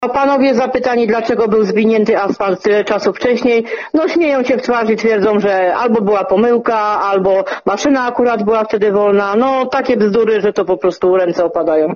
– Nie ma lamp, błoto jest tak potworne, że się w nim topimy […]. Mam zdjęcia, na których widać jakie koleiny były i dziury. Dzieci, które chodzą codziennie do szkoły toną w tym błocie – mówiła nam bielszczanka z Hałcnowa.